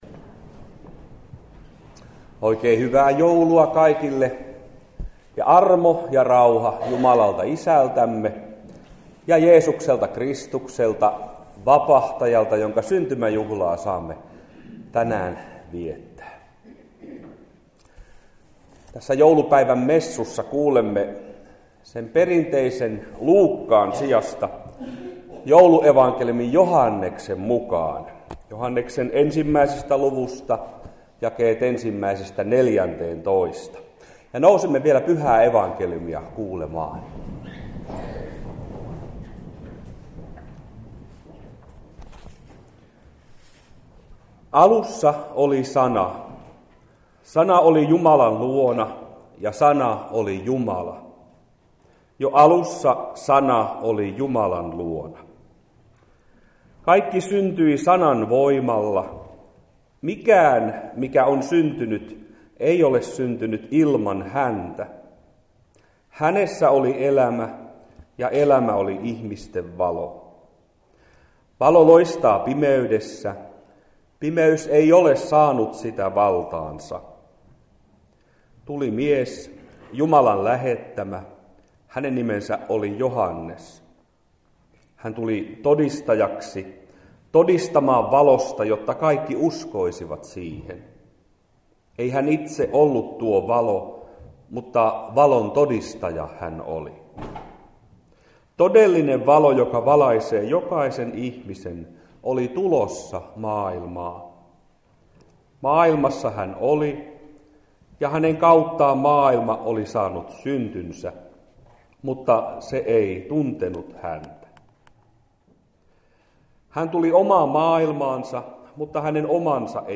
"Sana tuli lihaksi" -joulumessu Koskenkorvan seurakuntatalolla.
Saarna_Sana_tuli_lihaksi_joulu_2010_0.mp3